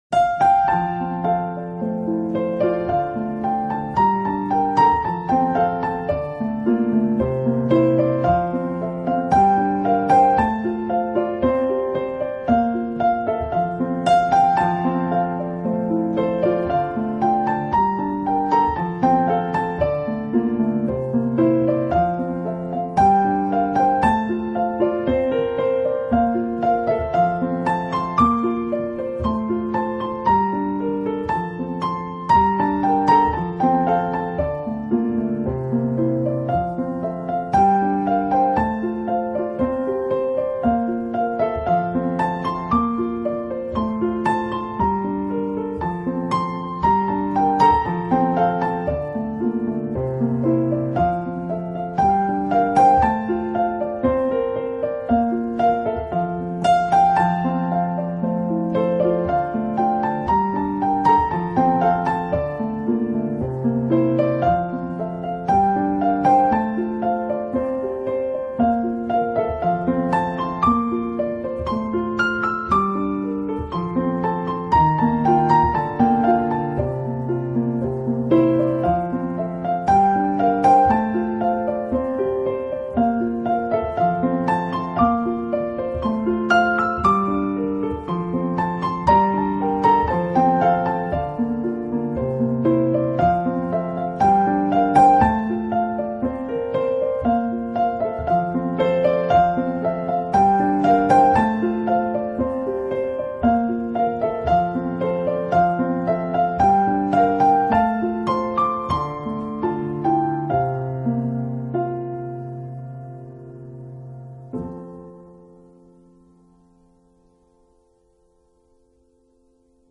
音乐类型: New Age
此碟极适合在冬季节日中播放，钢琴声音令人觉得温暖，也让人重温昔日New  Age殿堂乐手的风范。